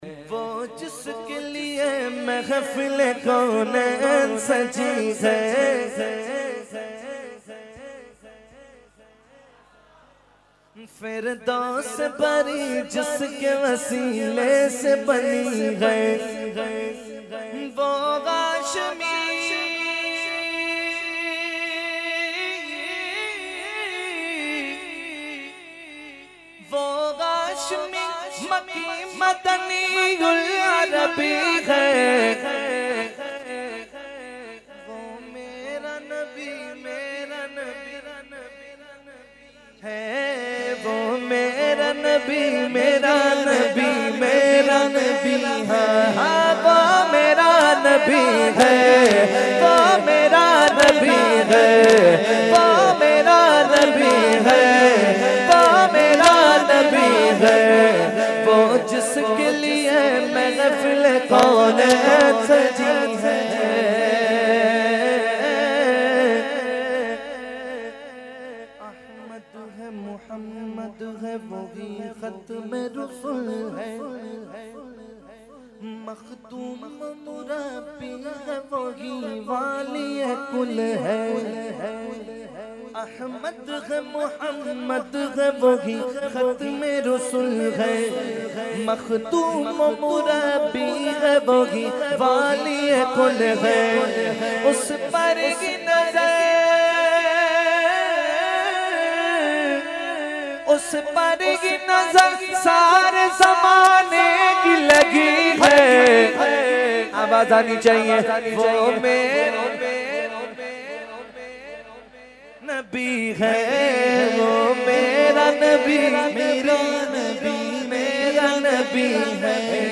Category : Naat | Language : UrduEvent : Urs Ashraful Mashaikh 2019